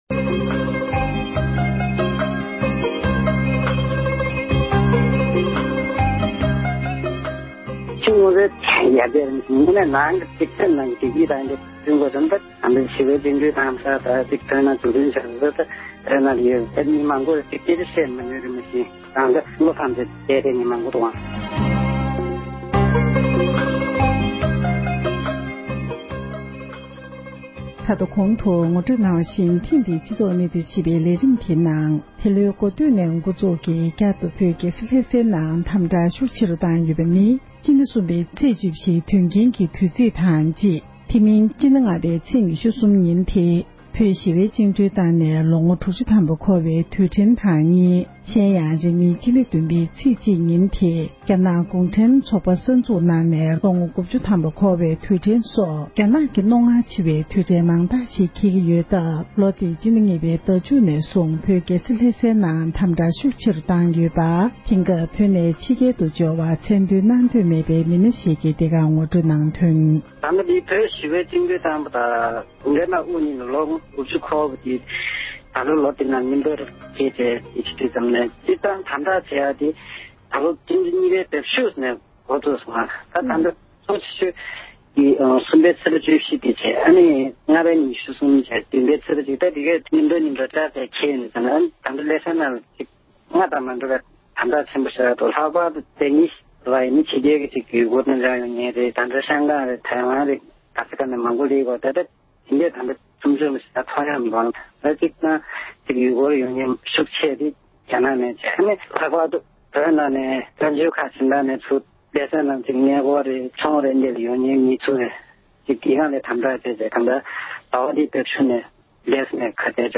འབྲེལ་ཡོད་མི་སྣར་གནས་འདྲི་ཞུས་ཏེ་གནས་ཚུལ་ཕྱོགས་སྒྲིགས་དང་སྙན་སྒྲོན་ཞུས་པ་ཞིག